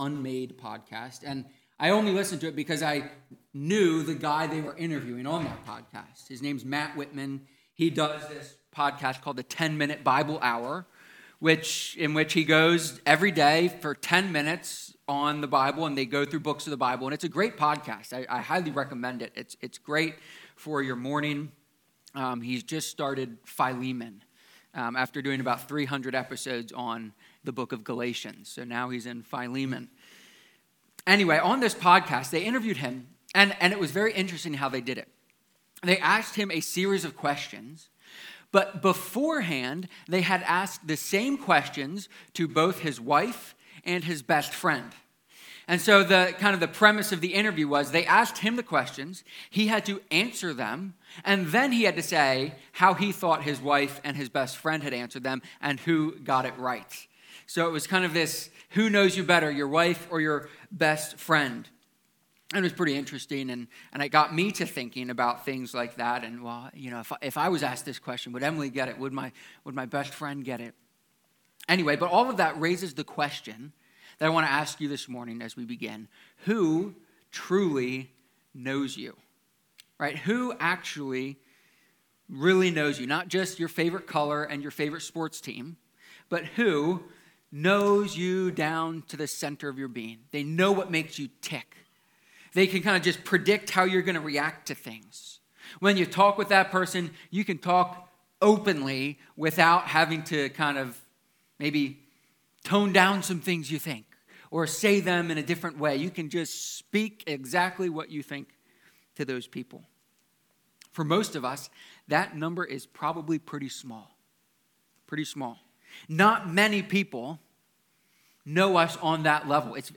psalm-139-sermon.mp3